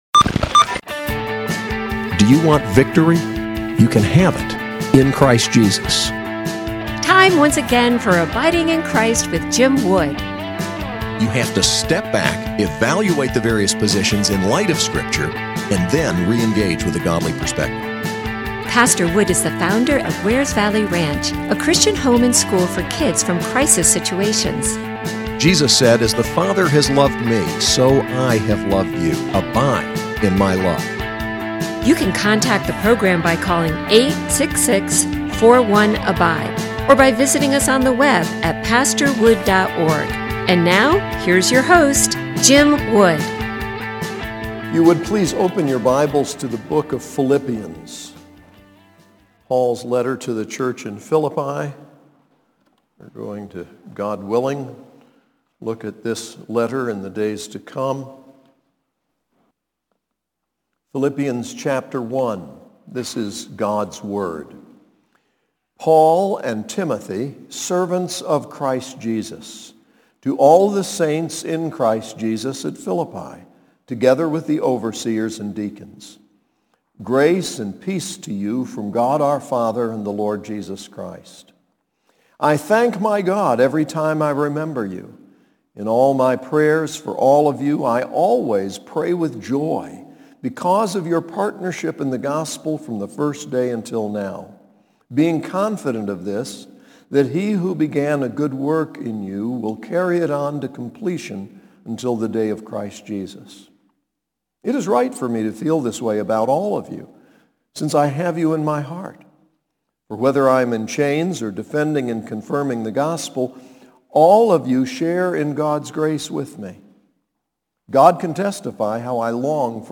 SAS Chapel: Philippians 1